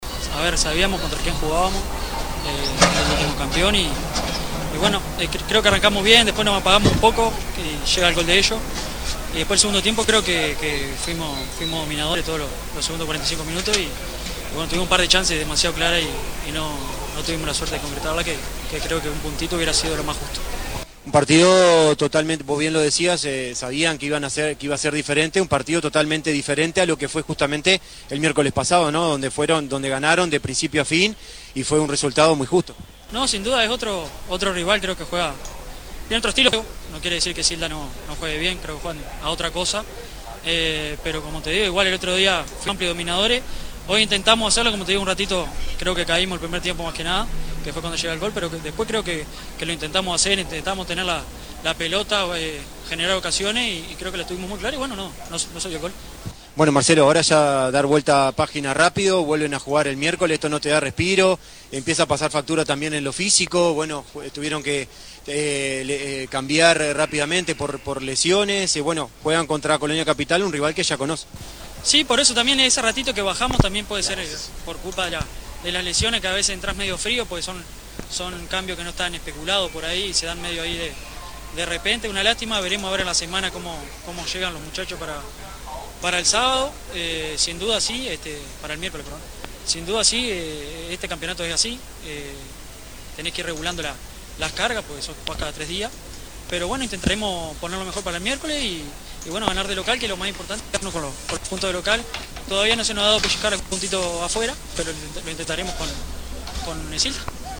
dialogamos con el futbolista